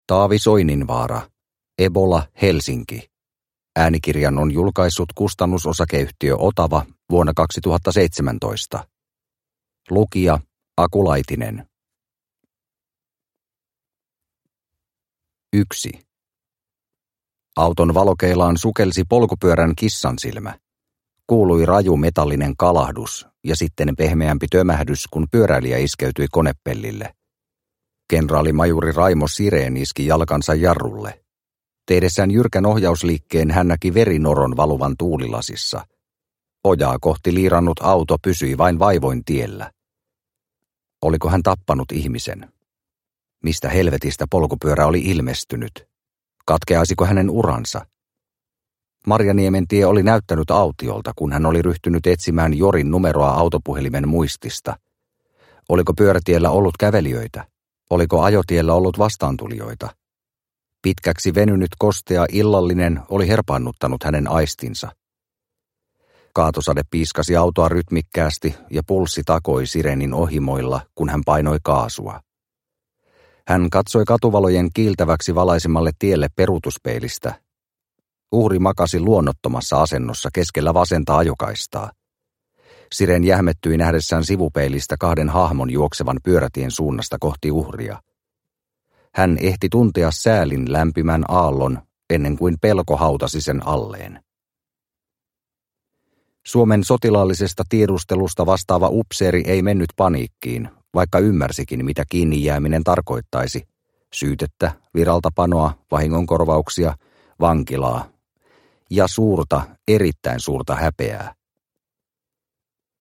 Ebola-Helsinki – Ljudbok – Laddas ner